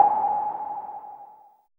JUNO NOISE2.wav